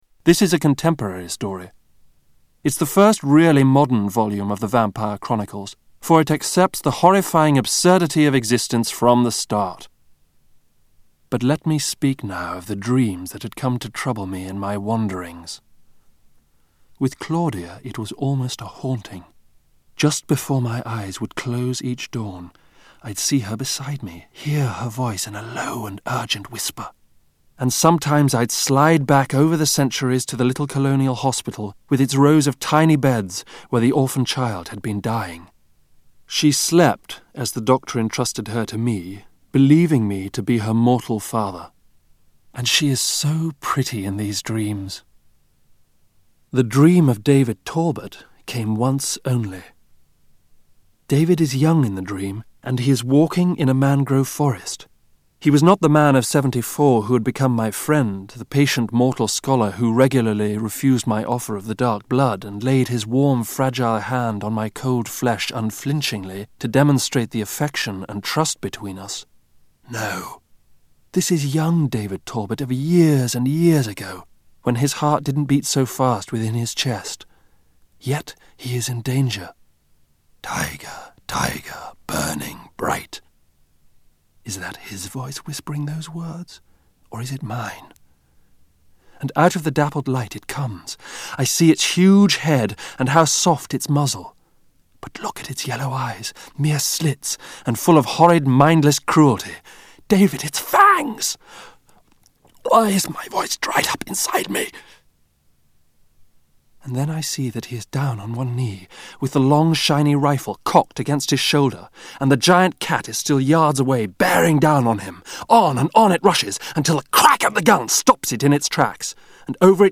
Tags: Media Author Anne Rice Interview with the Vampire Audio Books